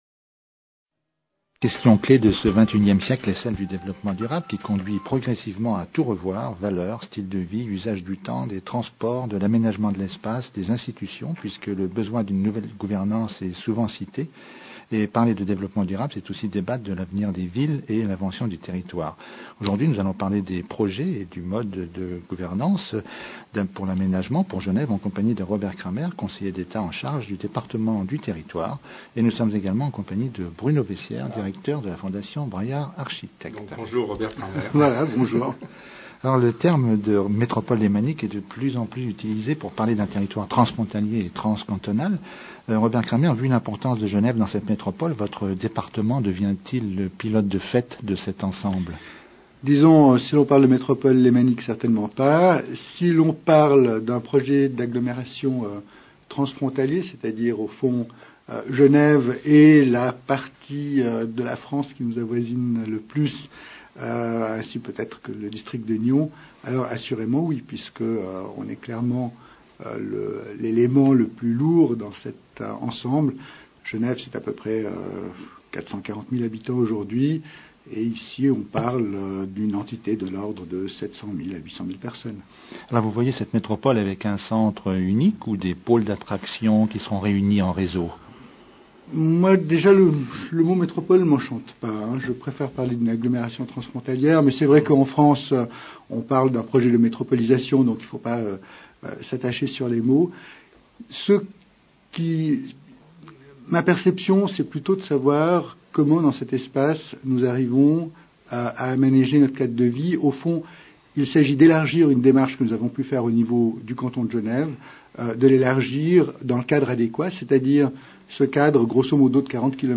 Avec Robert Cramer, Conseiller d’Etat en charge du Département du territoire.
Cité Culture. Une émission de Radio Cité, du lundi au vendredi, de 16h à 17h.